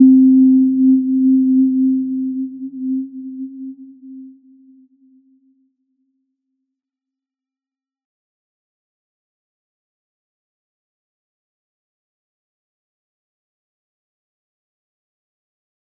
Little-Pluck-C4-p.wav